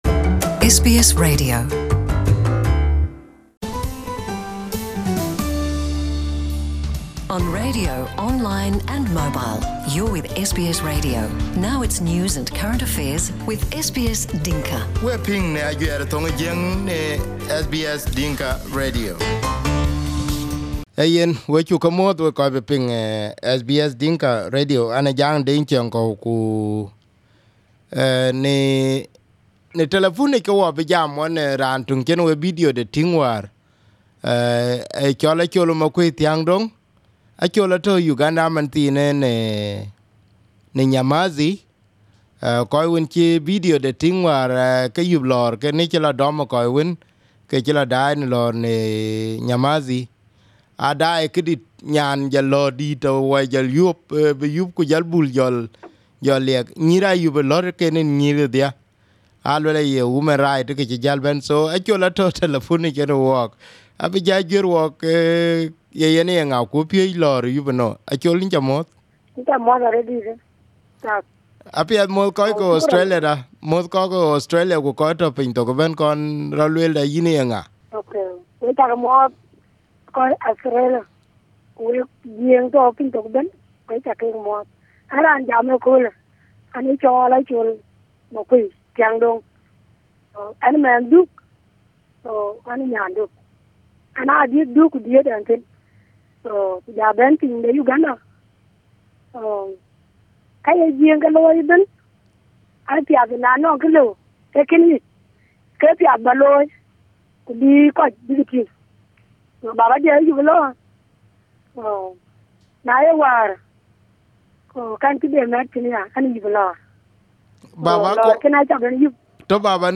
SBS Dinka with the help of other people who knew the where about gave us her phone.